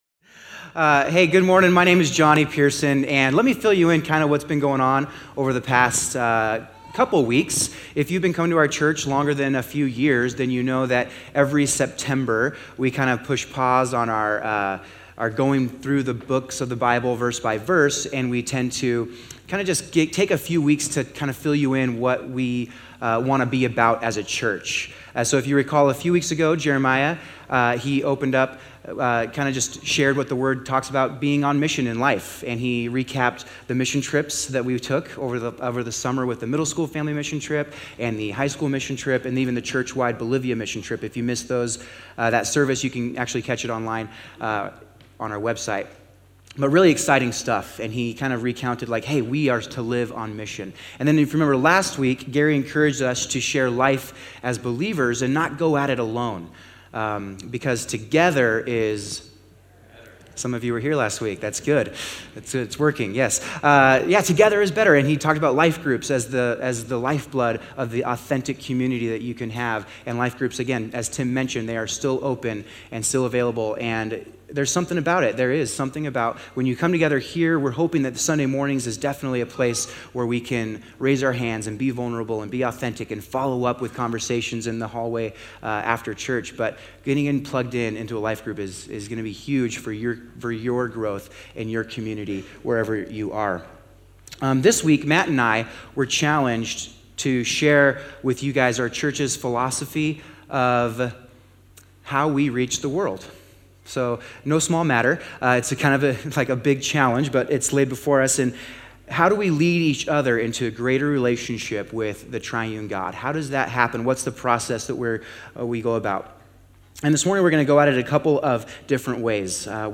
Be sure to check out this most recent co-hosted sermon for more details.